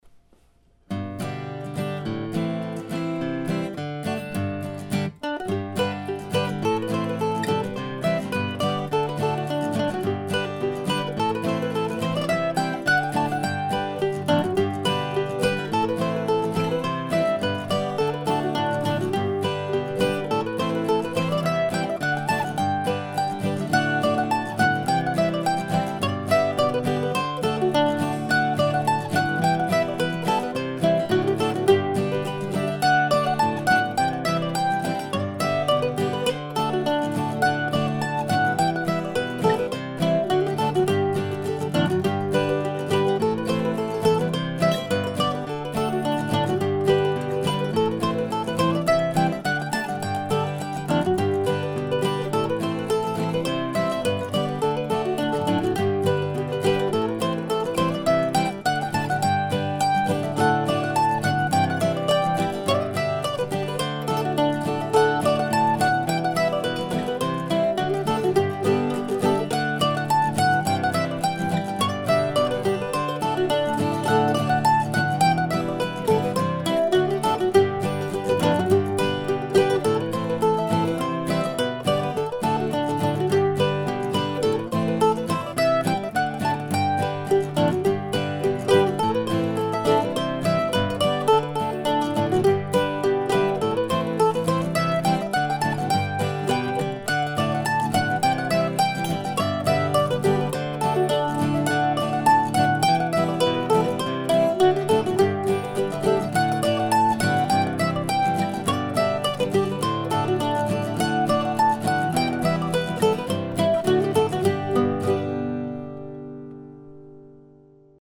To me it seems like a very English tune.